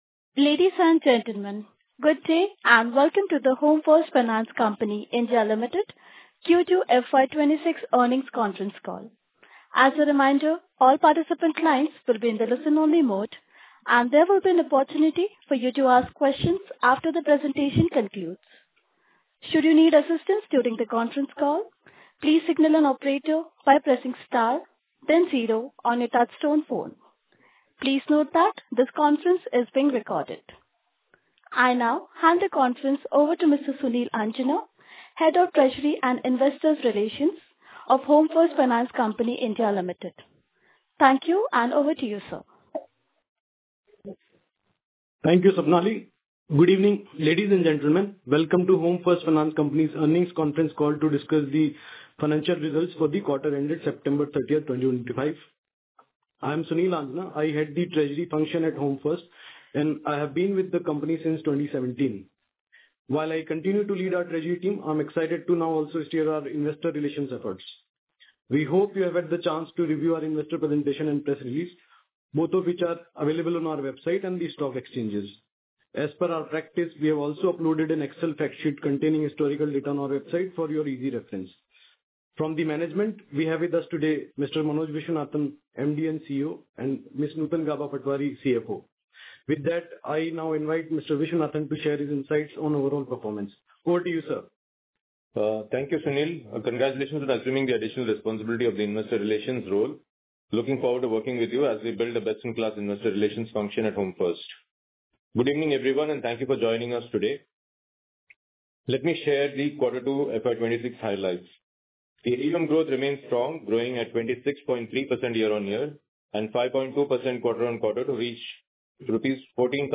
Investor Call Audio